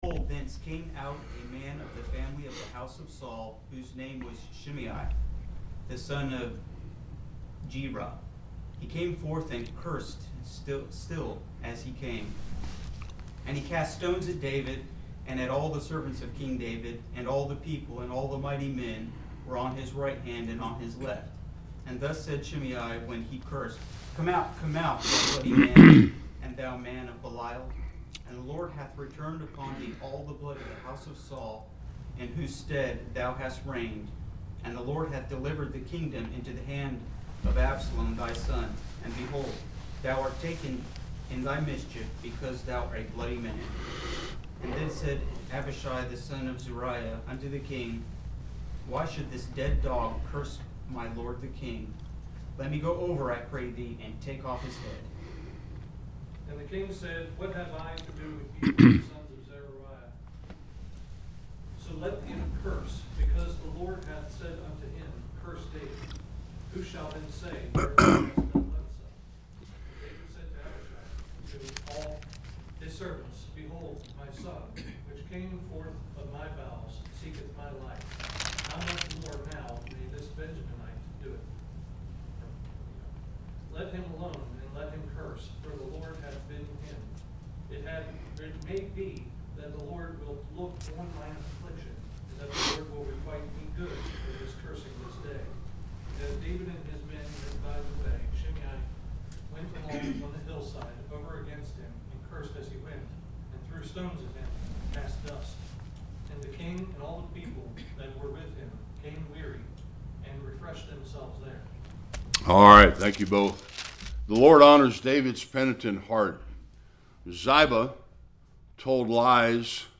1-10 Service Type: Sunday School Absalom seeks counsel on how to get rid of King David